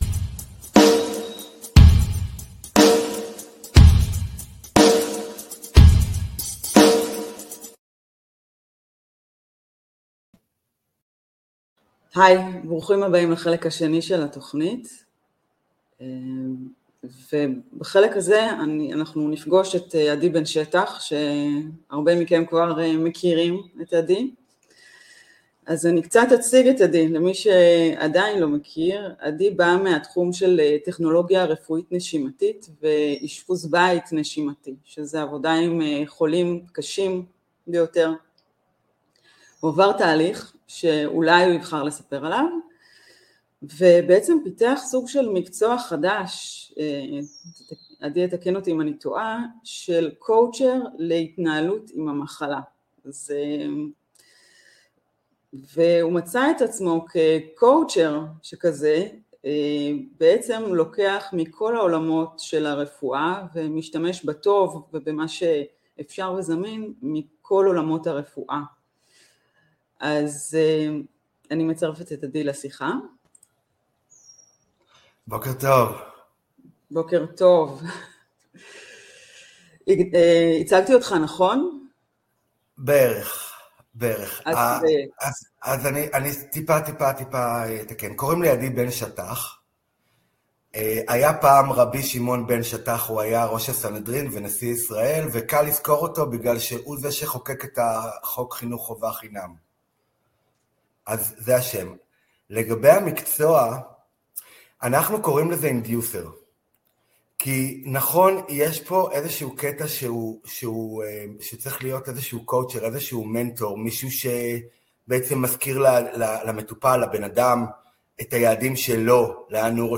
מראיינת